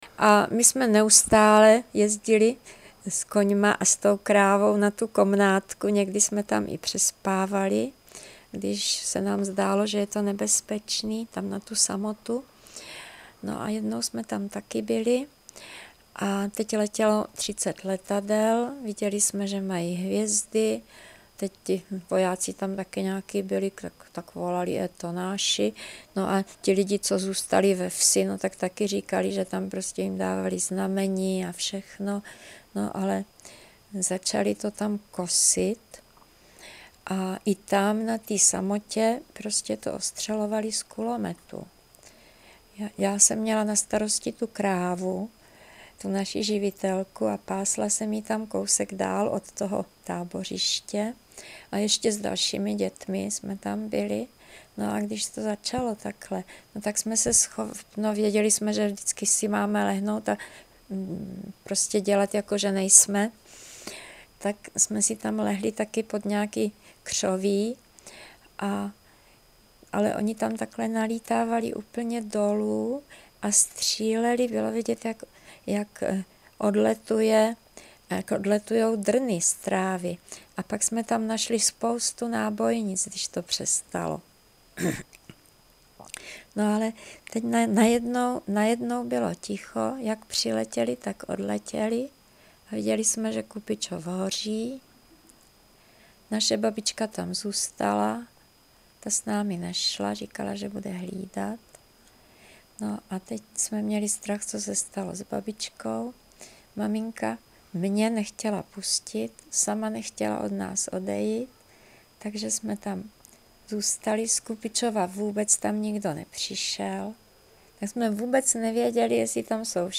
Klip z vyprávění